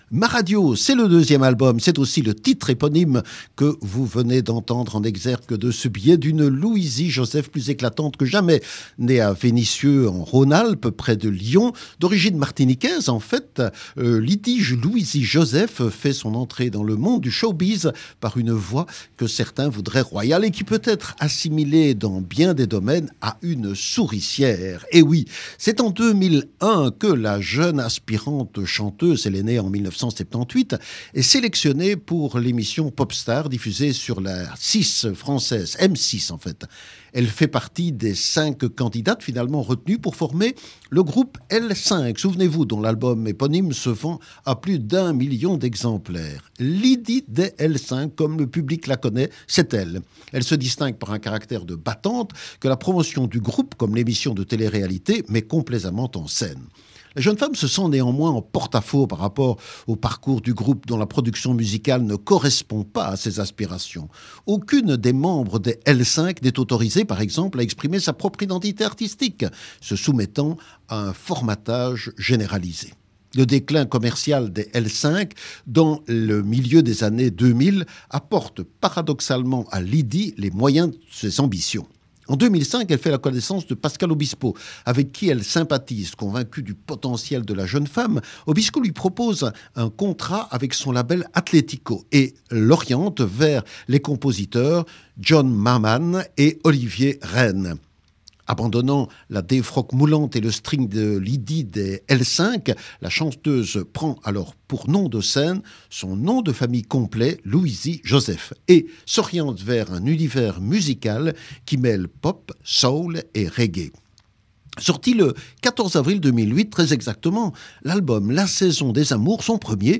Un fil conducteur entre pop, soul et reggae.